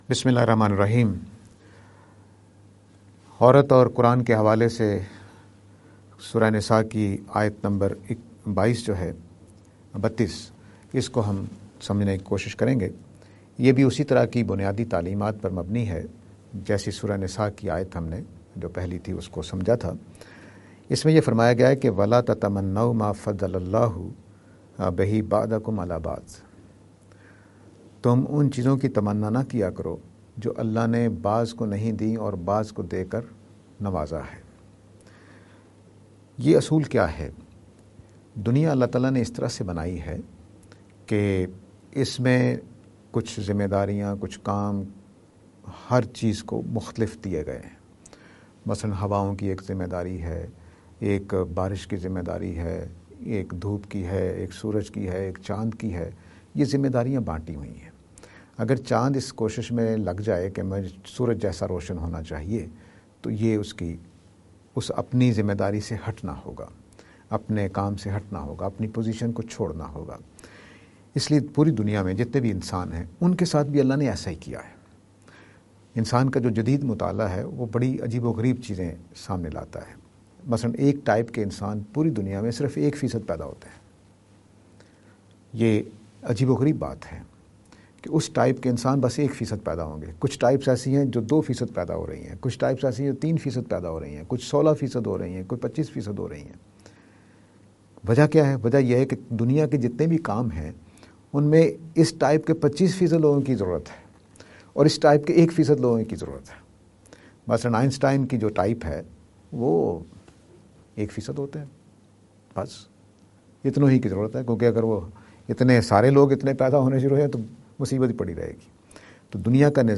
lecture series on "Women and Islam".